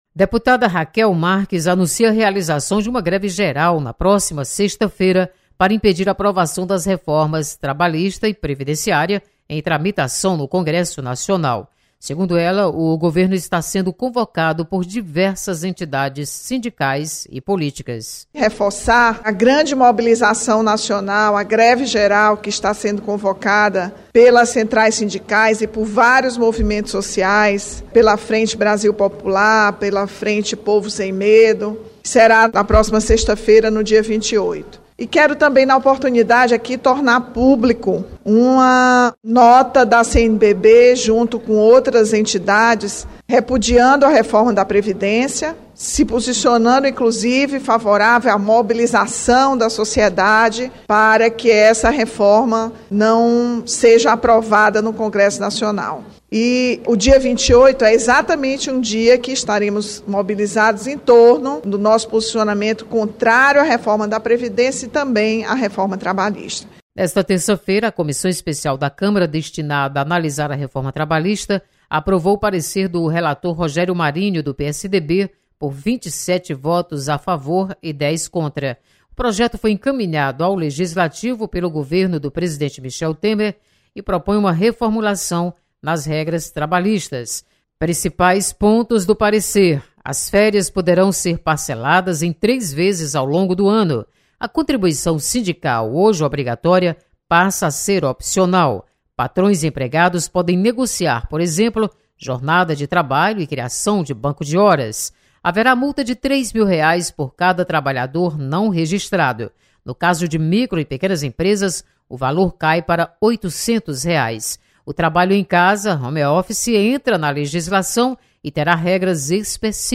Greve Geral é defendida pela deputada Rachel Marques. Repórter